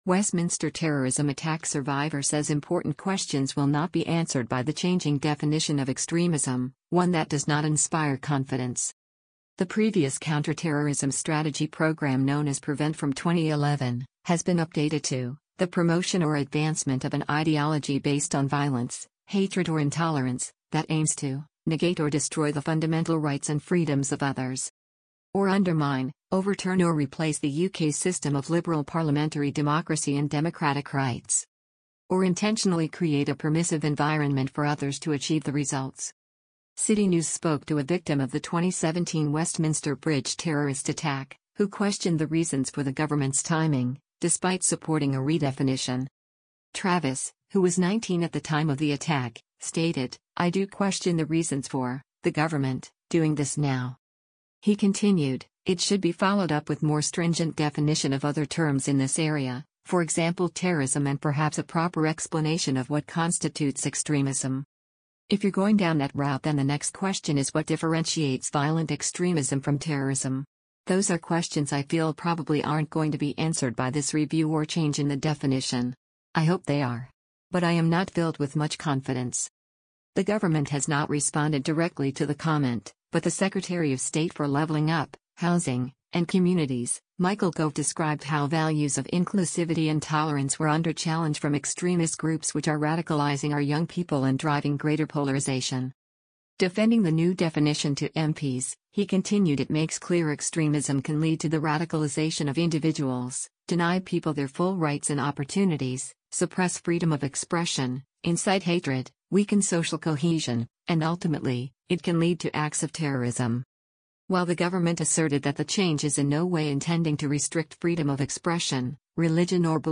Listen to this article powered by AI.